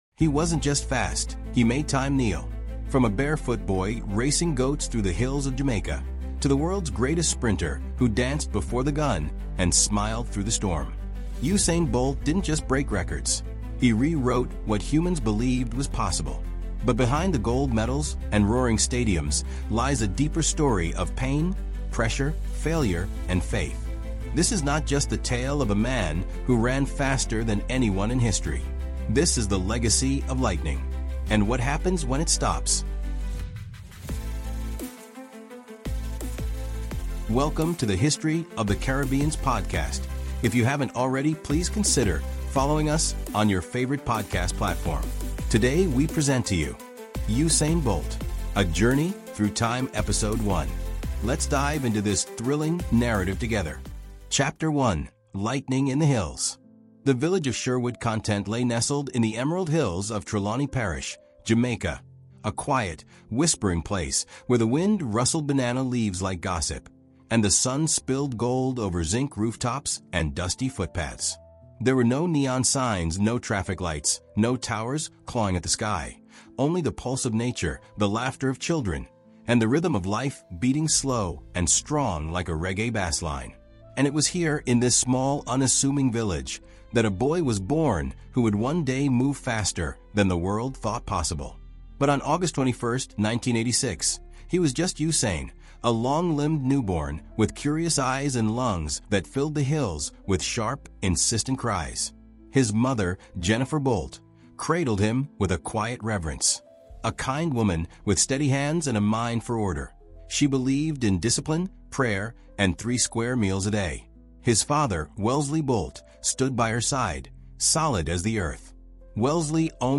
From his humble beginnings on the dusty roads of Sherwood Content, Jamaica, to the roaring stadiums of Beijing, London, and Rio, Usain Bolt: The Legacy of Lightning is the definitive 16-chapter storytelling audiobook chronicling the rise, reign, and remarkable afterlife of the fastest man in history.
With immersive narration, cinematic pacing, and emotional depth, this series explores the triumphs, injuries, doubts, and dreams that shaped his journey.